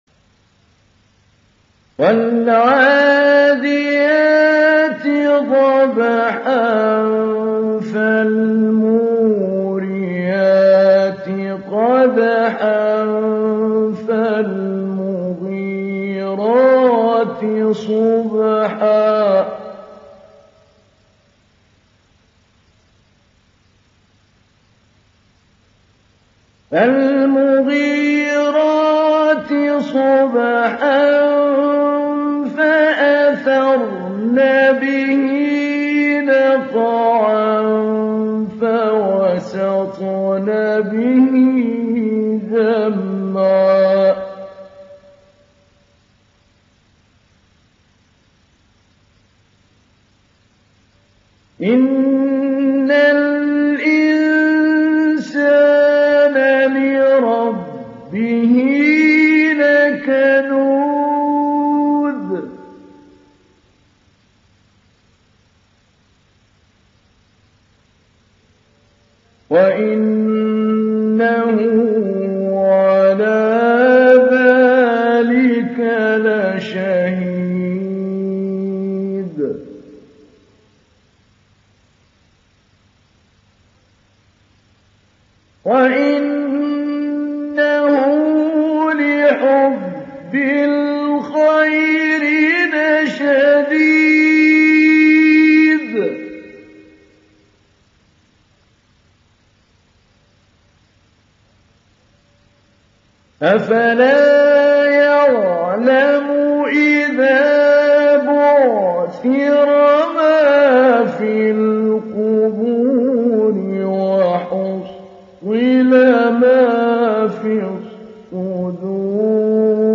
تحميل سورة العاديات mp3 بصوت محمود علي البنا مجود برواية حفص عن عاصم, تحميل استماع القرآن الكريم على الجوال mp3 كاملا بروابط مباشرة وسريعة
تحميل سورة العاديات محمود علي البنا مجود